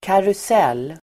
Uttal: [karus'el:]